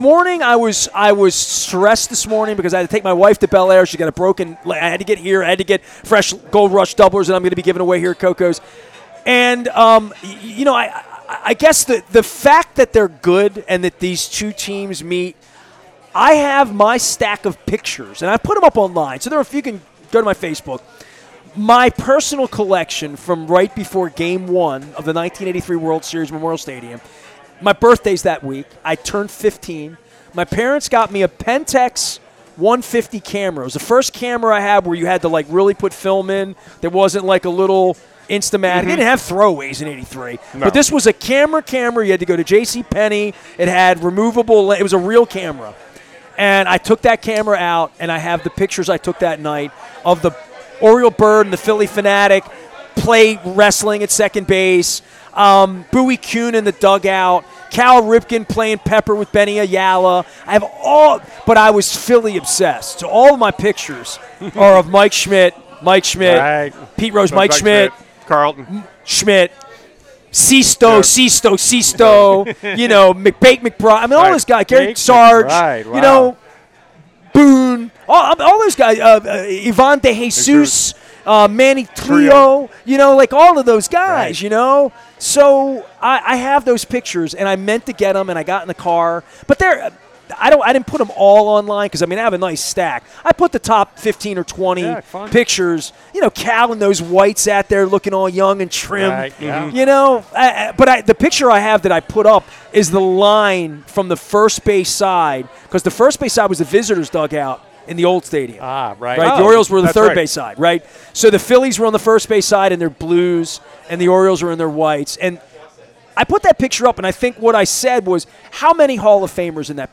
As our Maryland Crab Cake Tour returned to Koco's Pub and Lauraville during Pride Week